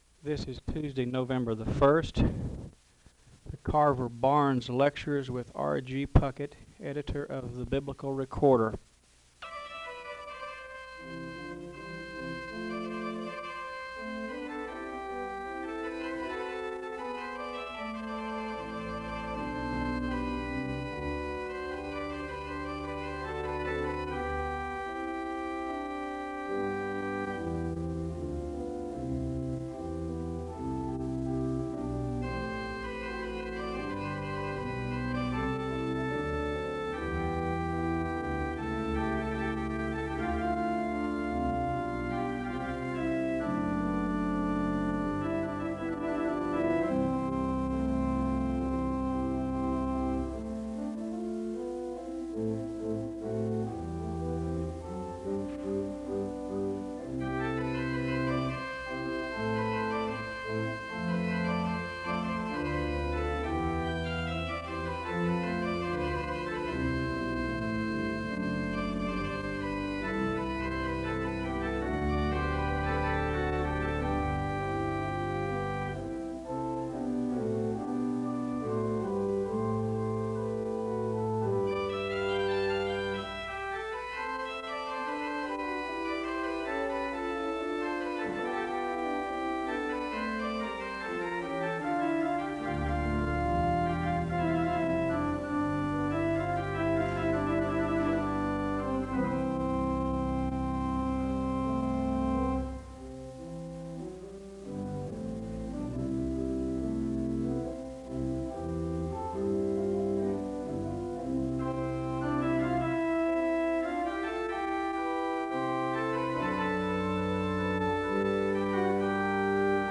A hymn is played (0:09-3:36).